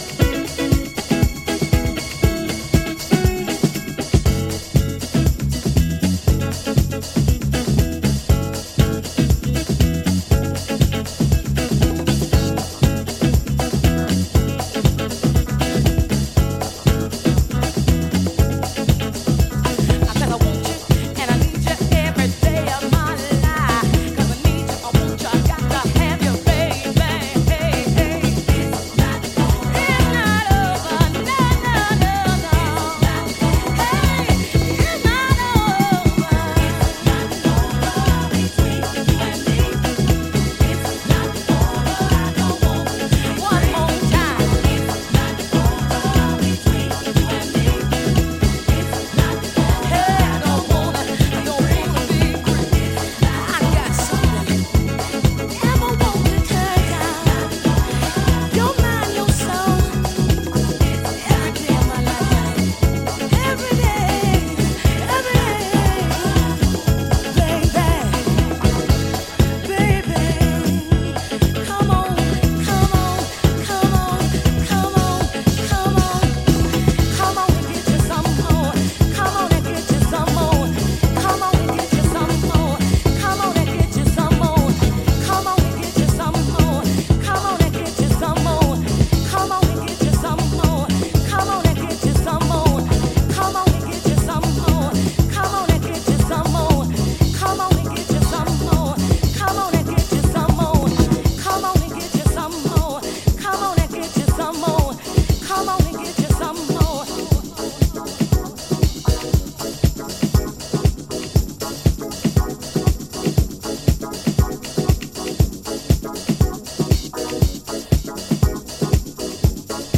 ジャンル(スタイル) DISCO / SOUL / FUNK / RE-EDIT